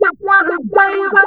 VOX FX 4  -L.wav